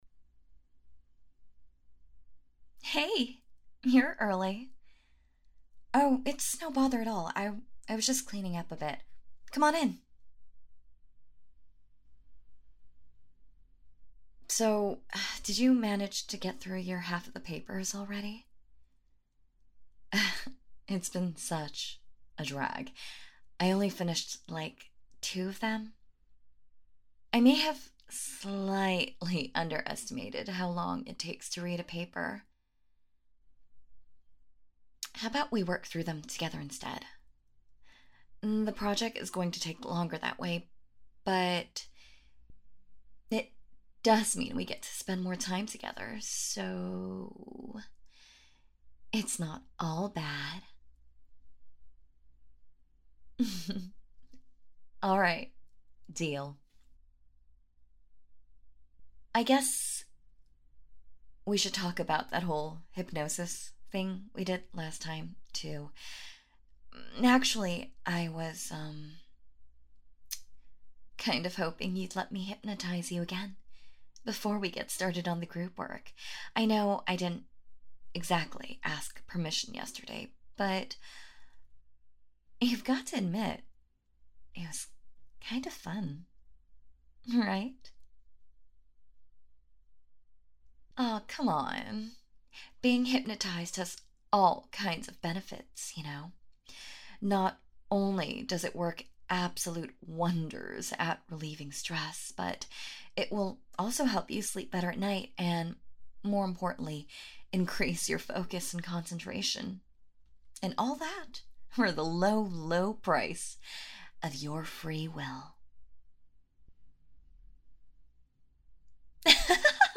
I love this series. Its very calming and great sleep aid.